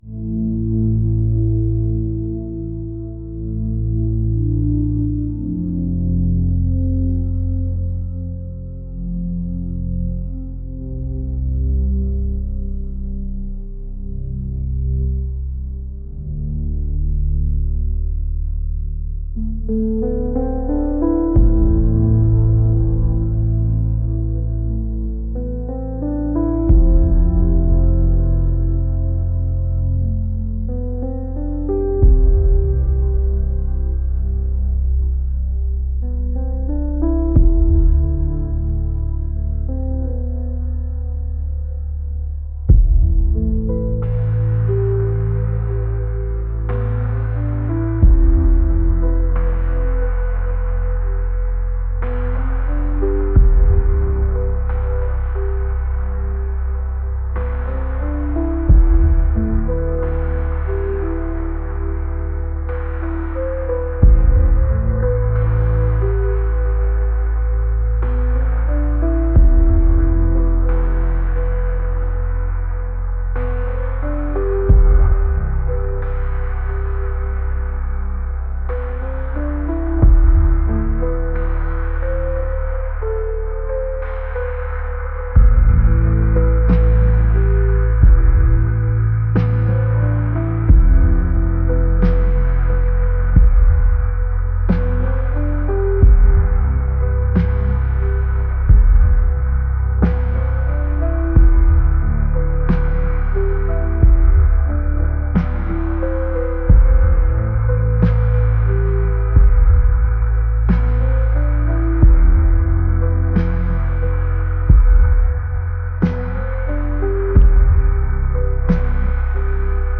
atmospheric | ambient